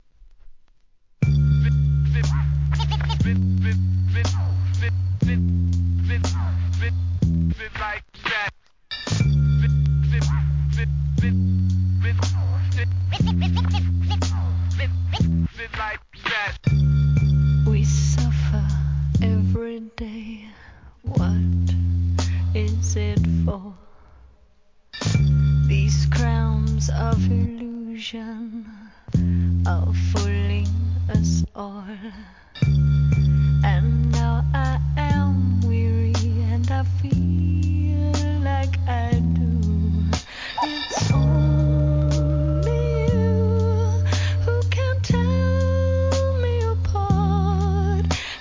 重たいBEATにスクラッチと言ったヒップホップなテイストで独特の世界です!